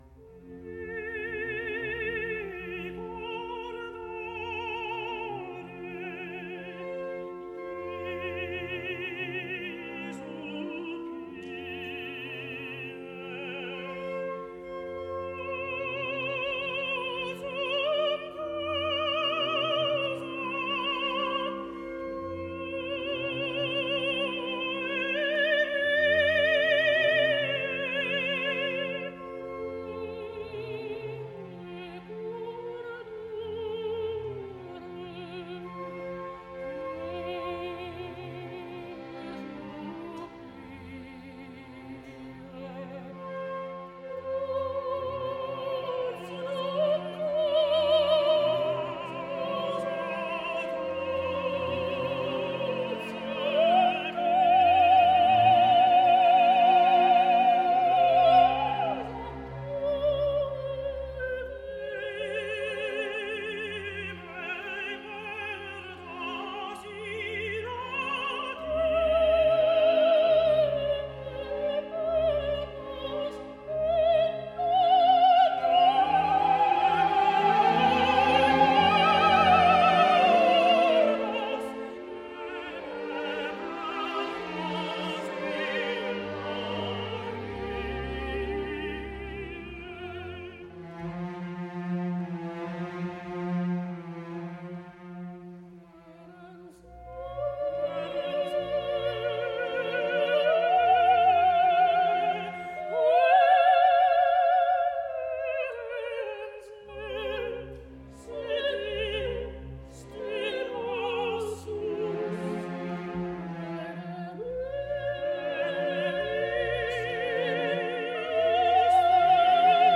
他的嗓音丰满、充沛、带有透明感，中高声区统一，音色宽厚，带有自然美感。音量大、力度强。